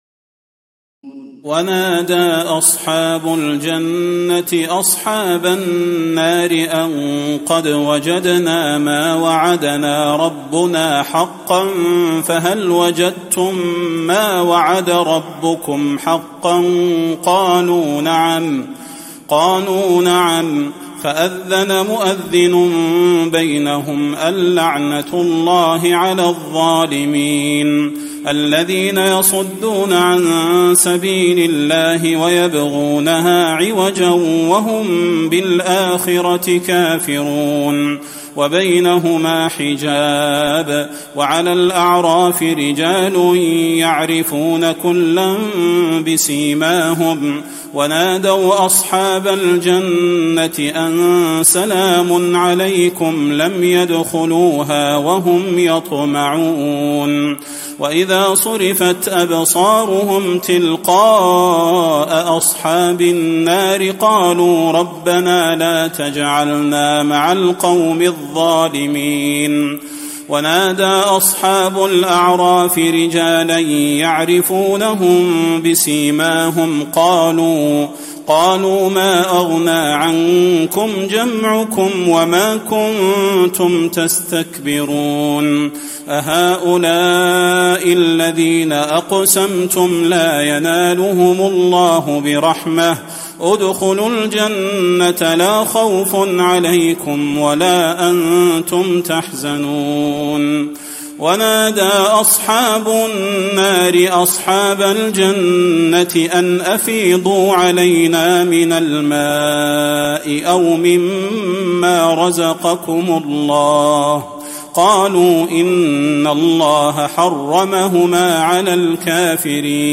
تراويح الليلة الثامنة رمضان 1435هـ من سورة الأعراف (44-147) Taraweeh 8 st night Ramadan 1435H from Surah Al-A’raf > تراويح الحرم النبوي عام 1435 🕌 > التراويح - تلاوات الحرمين